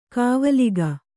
♪ kāvaliga